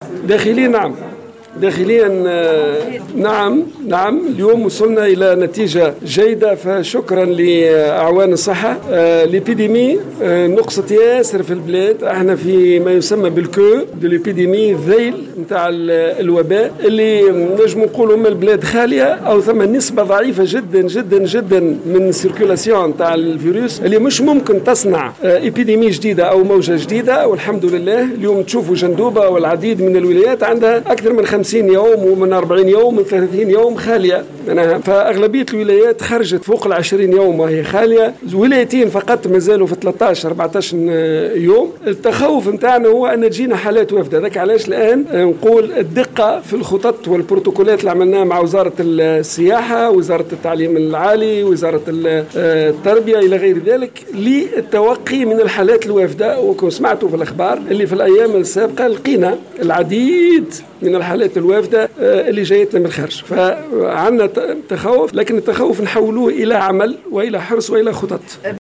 وأكد وزير الصحة، عبد اللطيف المكي، في تصريح لمراسلة الجوهرة أف أم بالمناسبة، أن تونس باتت اليوم شبه خالية من فيروس كورونا المستجد، ووصلت أخيرا إلى مرحلة ما يسمى بـ"ذيل الوباء" queue d'épidémie ، حيث تراجع نسق انتقال الفيروس إلى نسب ضعيفة جدا، بما لا يدع مجالا للتخوف من موجة عدوى جديدة.